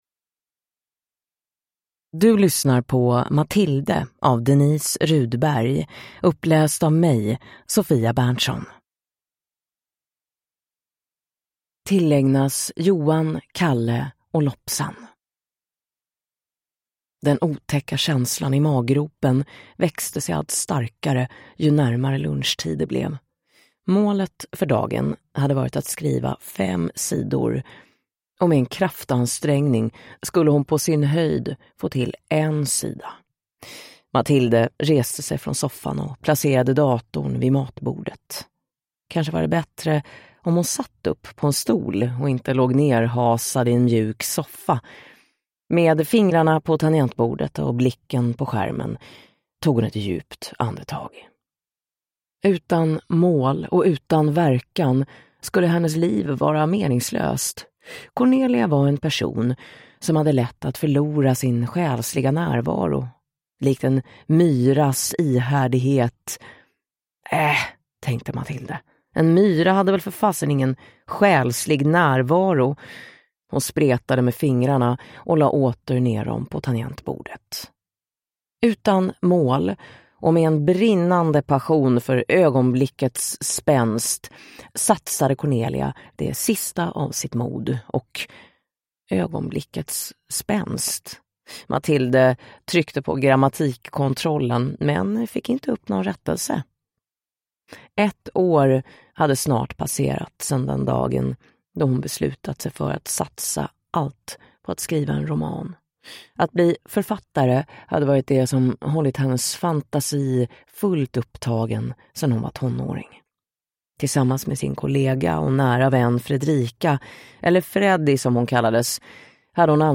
Matilde (ljudbok) av Denise Rudberg